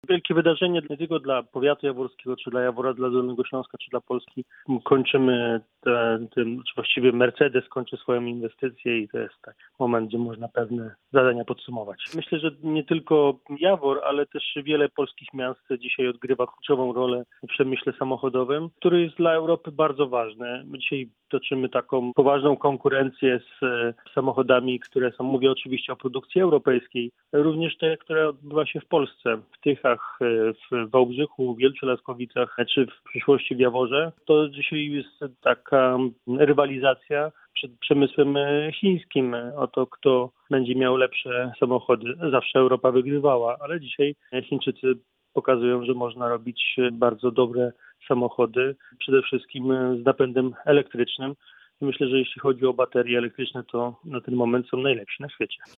Michał Jaros – wiceminister rozwoju i technologii, szef dolnośląskich struktur KO był dziś naszym „Porannym Gościem”.